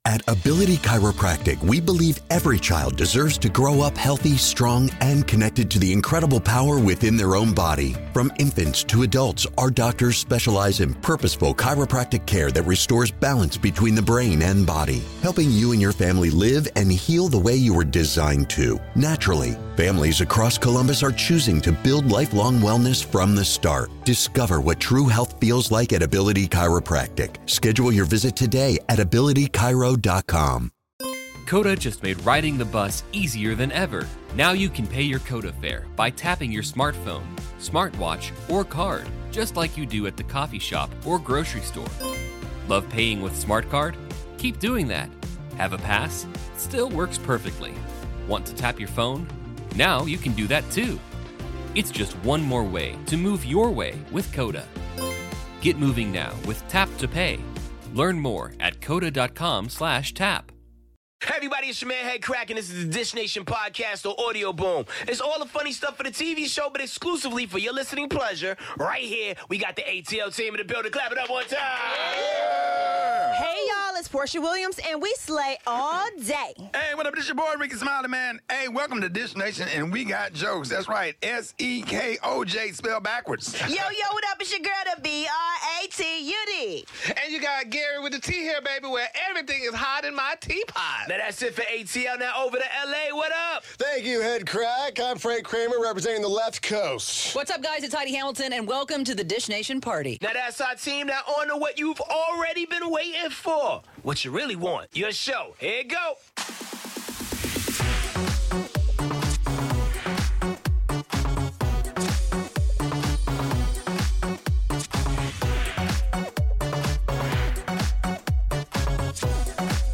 Guest co-host: Amber Nash from the hit animated comedy 'Archer.' The best moments from the royal wedding & the Billboard Music Awards. Plus all the latest on Snoop Dogg, Kanye West, Chrissy Teigen, John Legend, Kim Kardashian, Jennifer Aniston and more.